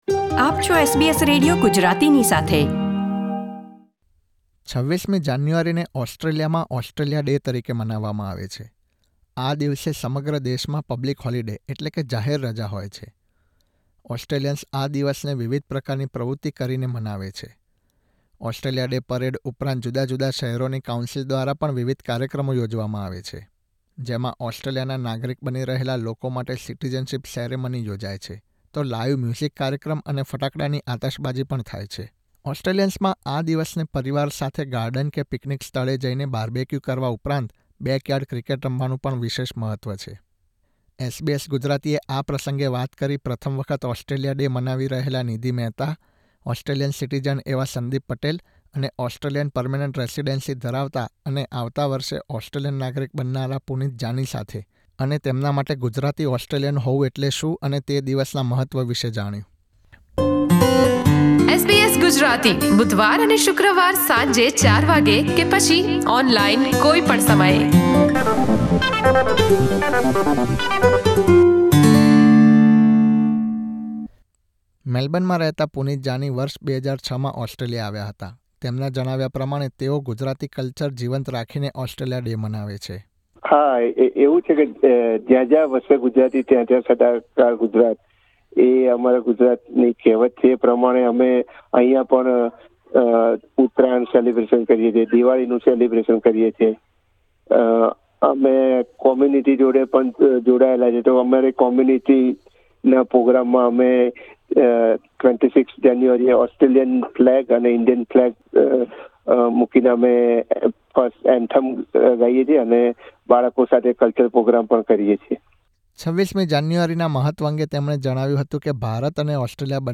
ઓસ્ટ્રેલિયામાં સ્થાયી થયેલા ગુજરાતી માઇગ્રન્ટ્સ 26મી જાન્યુઆરીએ ઓસ્ટ્રેલિયાના ડેના દિવસે વિવિધ કાર્યક્રમો યોજીને, સિટીઝનશિપ સેરેમનીમાં ભાગ લઇને અને ઓસ્ટ્રેલિયન રાષ્ટ્રગીત યાદ કરીને આ દિવસને મનાવશે. SBS Gujarati સાથેની વાતચીતમાં તેમણે ઓસ્ટ્રેલિયા ડેનું મહત્વ અને તેના આયોજન અંગે વાત કરી હતી.